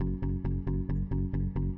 这些样本是使用各种硬件和软件合成器以及外部第三方效果创建的。